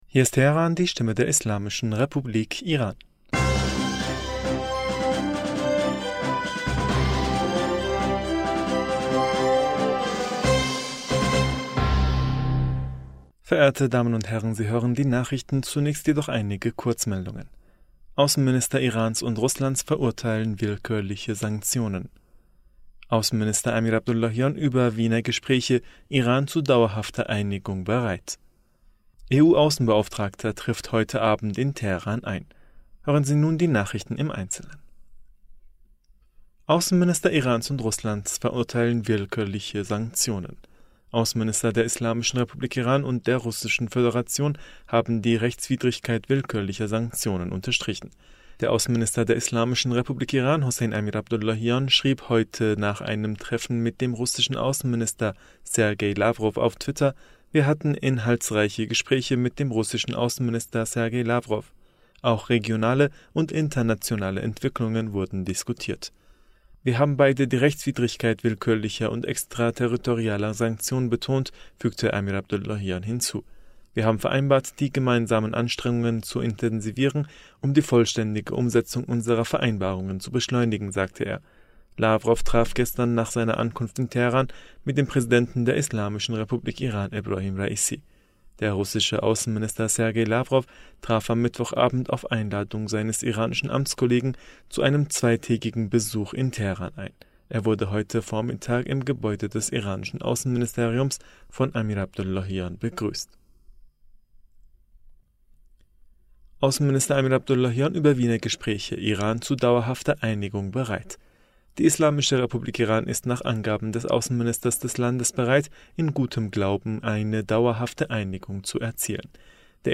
Nachrichten vom 24. Juni 2022